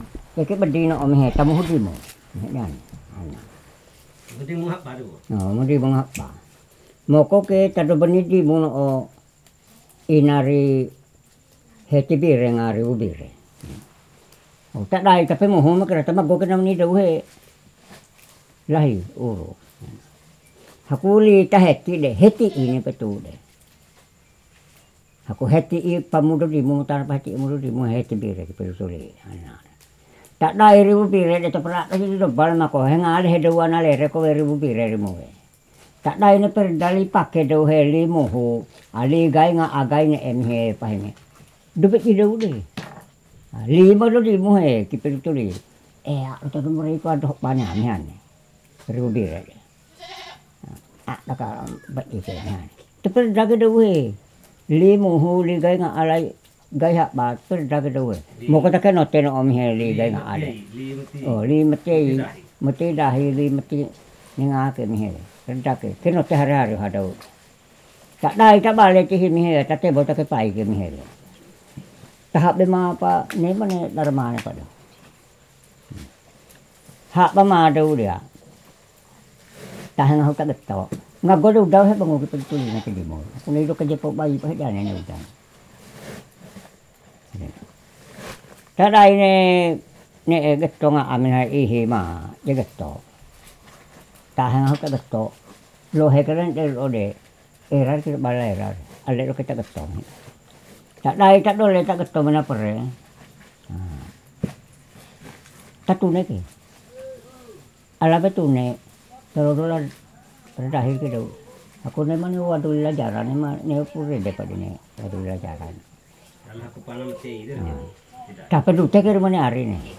A man tells a story
digital wav file recorded at 44.1 kHz/16 bit on Zoom H4N solid state recorder and Movo LV4-C cardiod
Raeliu, Savu, Nusa Tenggara Timur, Indonesia; recording made in Raeliu